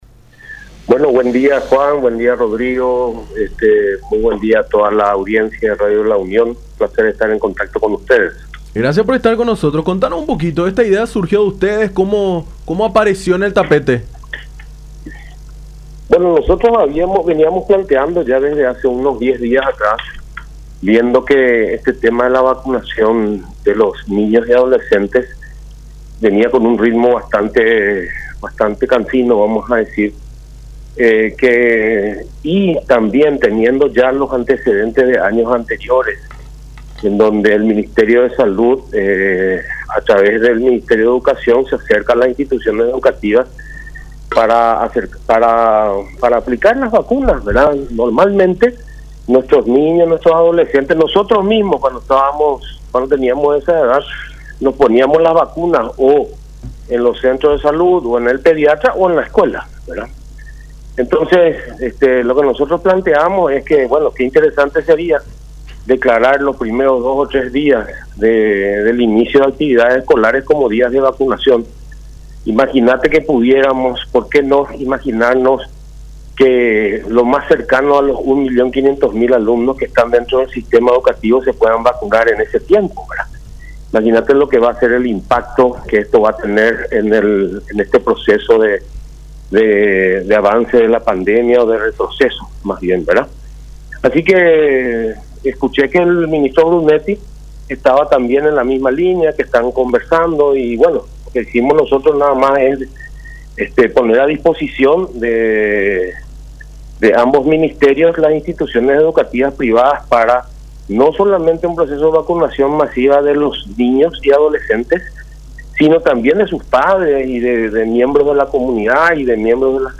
en charla con Nuestra Mañana por La Unión